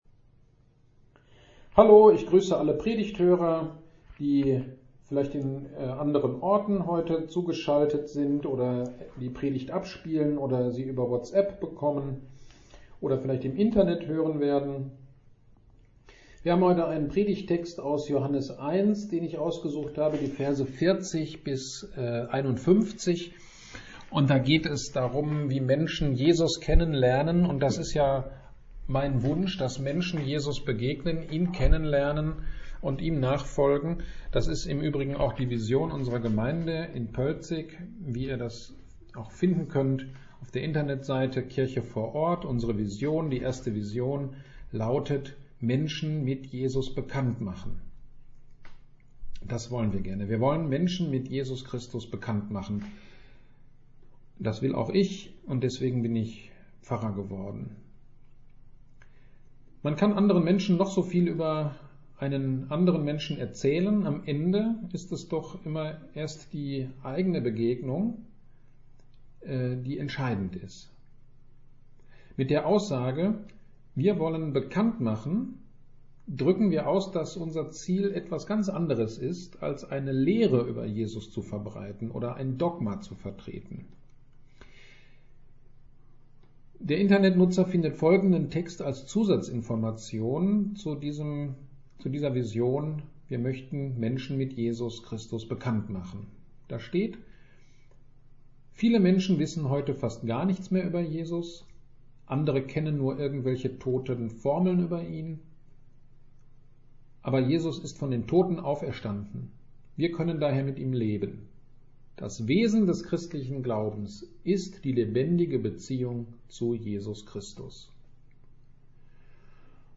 Predigt über Johannes 1, 40-51 zum Thema „Mission“